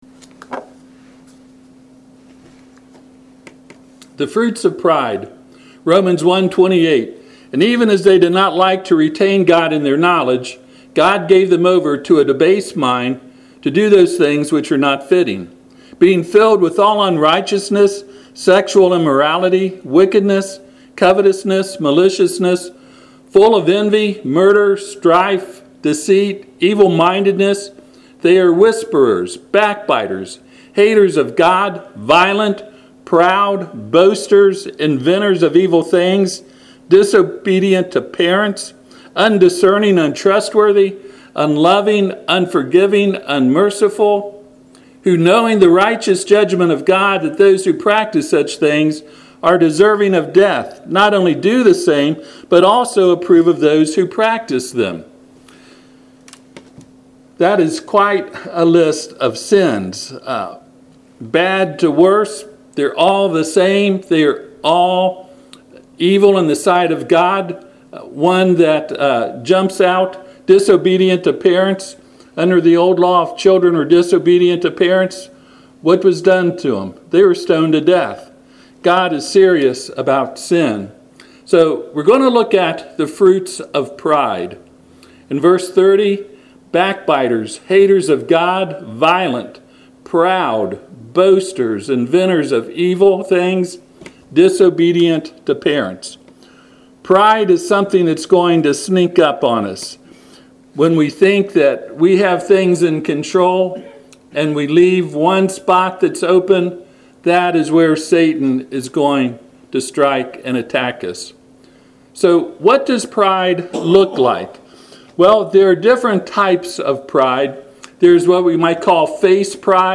Passage: Romans 1:28-32 Service Type: Sunday PM